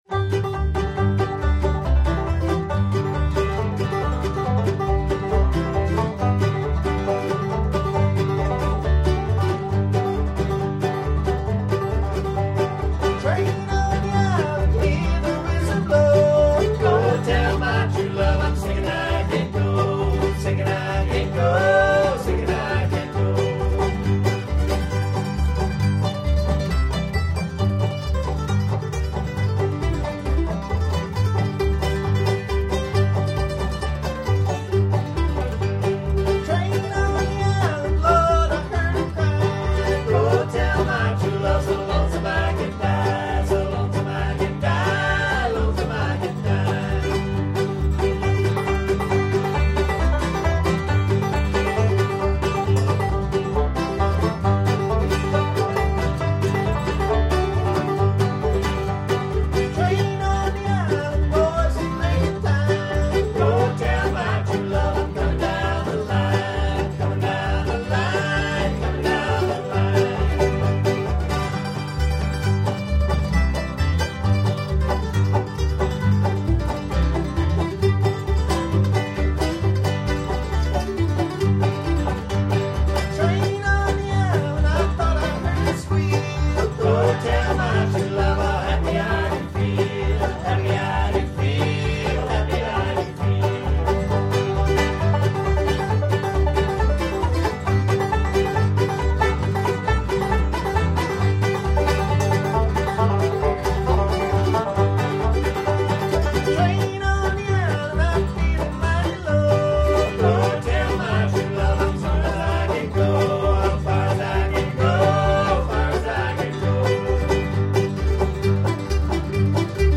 Little Rabbit is a band that performs old-time American songs and fiddle tunes.
banjo
guitar
bass
mandolin
All of us sing.